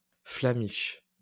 Flamiche (French: [fla.miʃ]